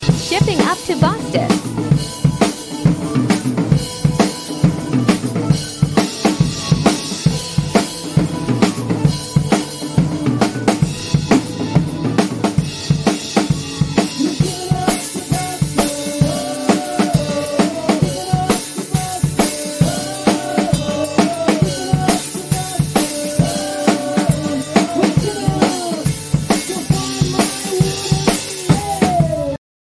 Drums clip#Irishband sound effects free download